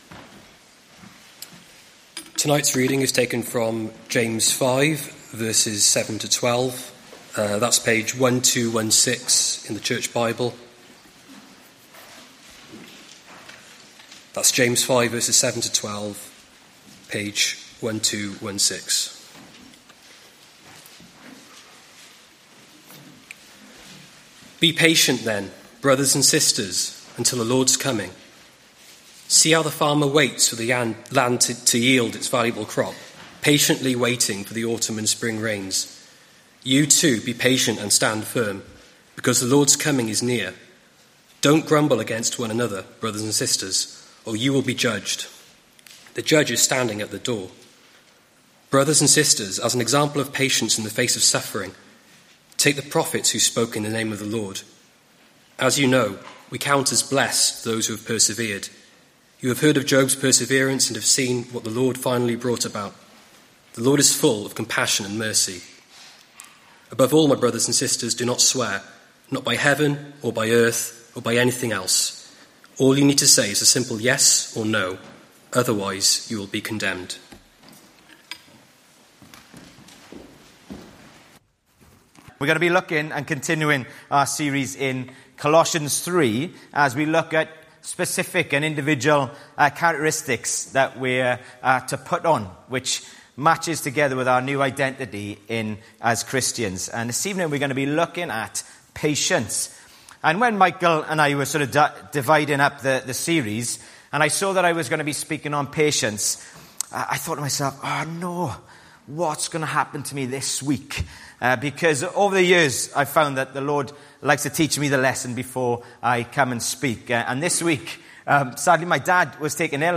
Colossians 3:13; James 5:7-12; 30 June 2024, Evening Service.